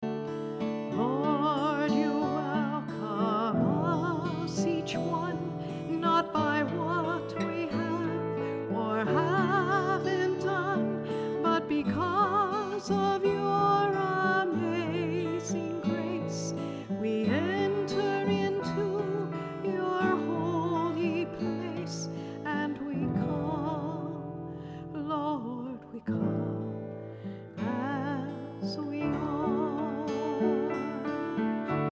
A collection of worship songs